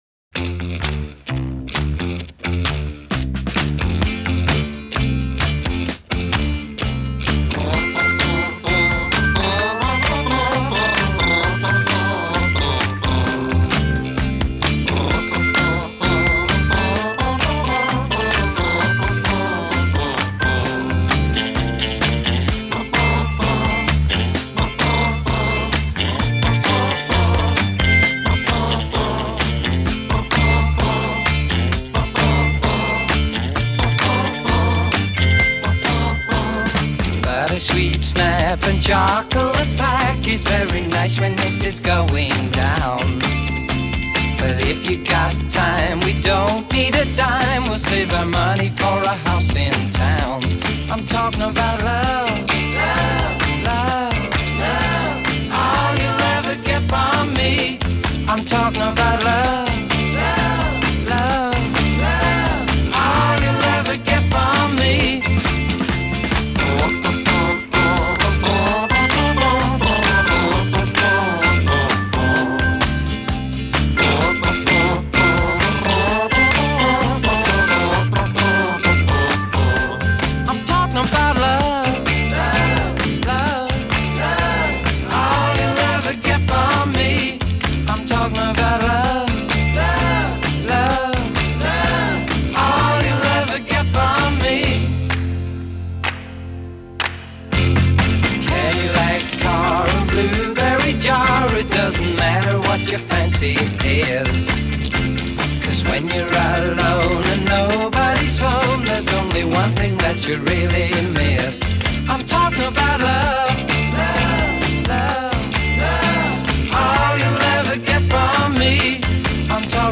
Genre: Classic Rock